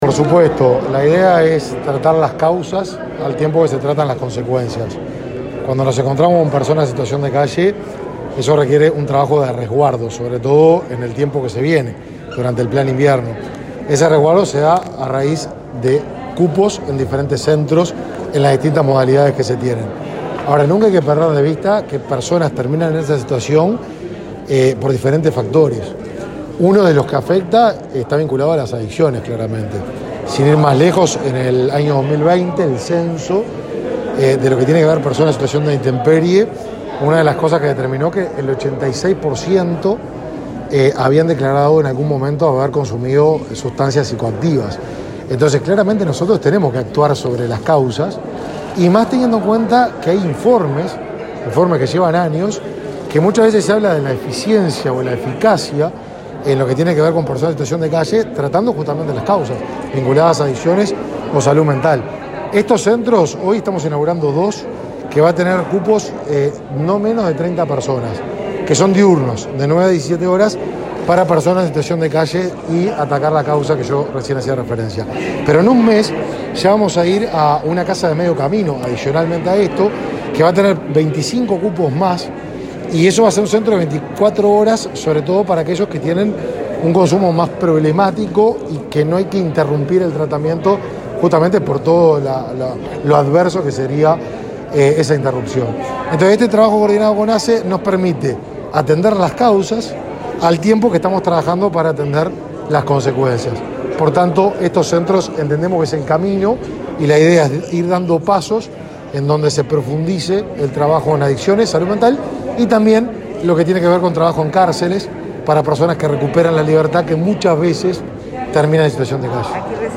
Declaraciones a la prensa del ministro Martín Lema
Declaraciones a la prensa del ministro Martín Lema 04/04/2022 Compartir Facebook X Copiar enlace WhatsApp LinkedIn El ministro de Desarrollo Social, Martín Lema, participó este lunes 4 en Montevideo, de la inauguración de dos centros diurnos para personas que padecen consumo problemático de drogas. Luego, dialogó con la prensa.